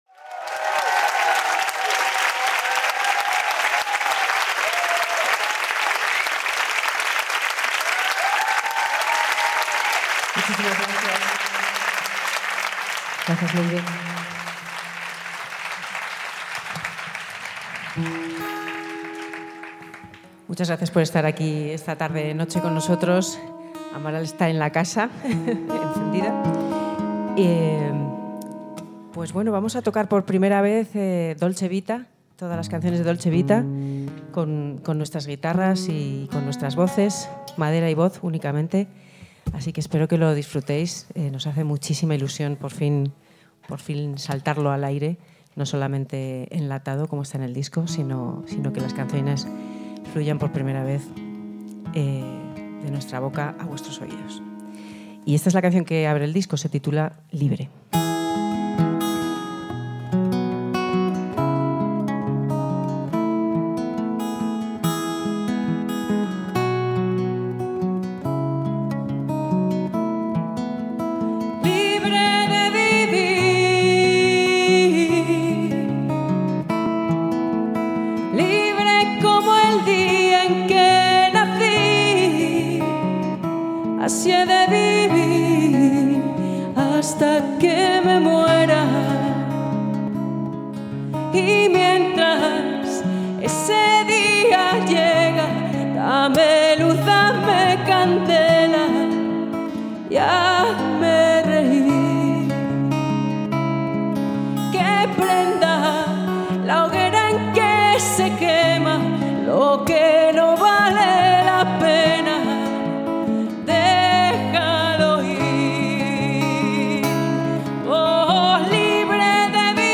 recorded during the Radio Encendida Festival, Madrid 2025
vocals
guitar